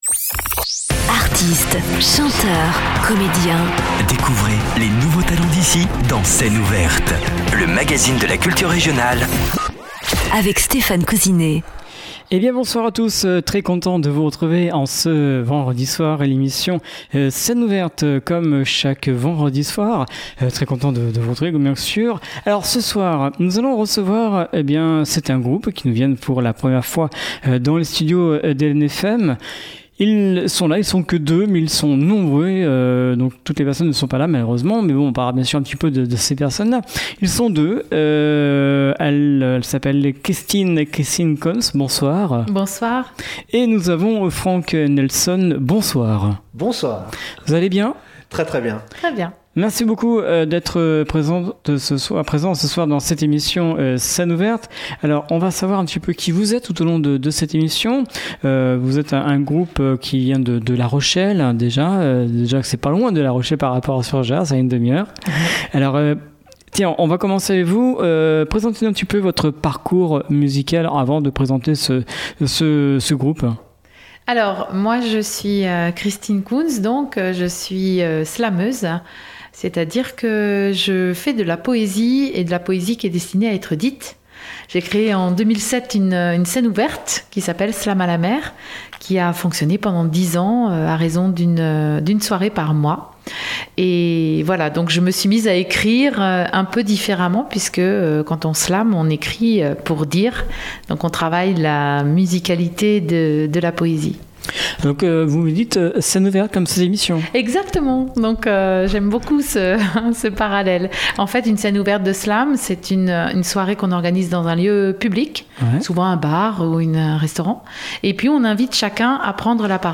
8 bassistes
un batteur, un percussionniste
Les interventions poétiques slamées sont ce lien.